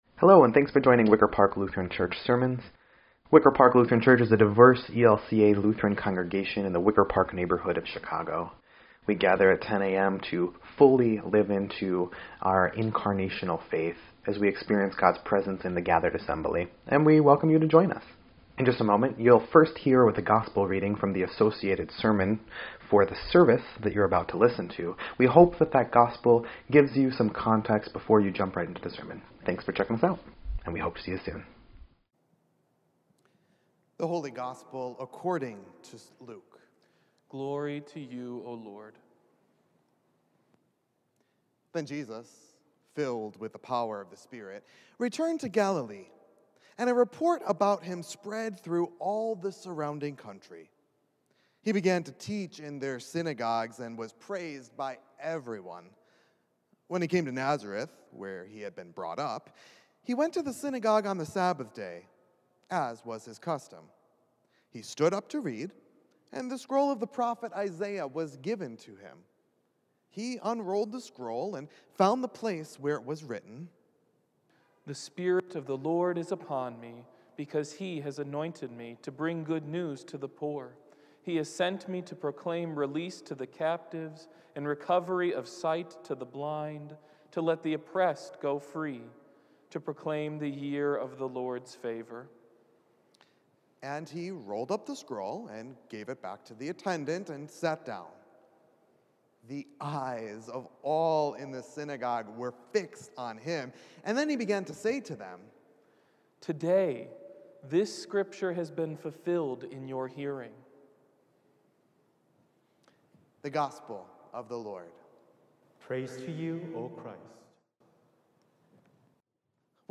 1.23.22-Sermon_EDIT.mp3